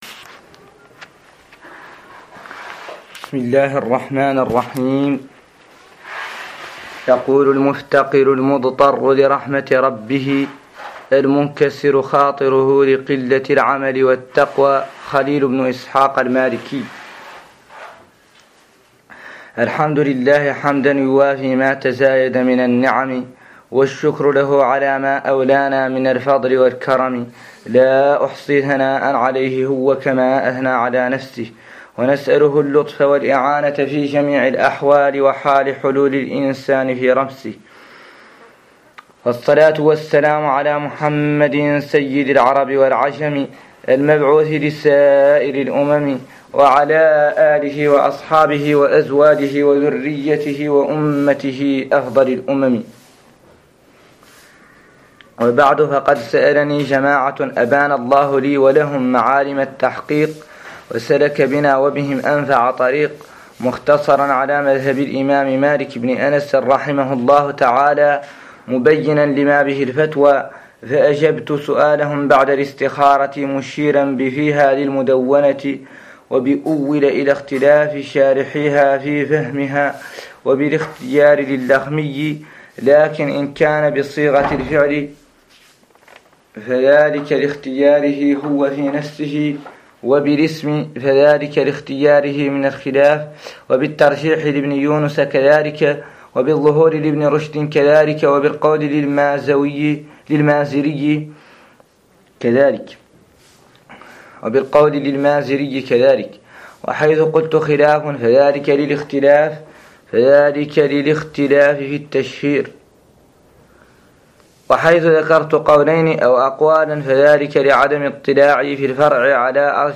قراءة لمتن مختصر خليل 01
قراءة لمتن مختصر خليل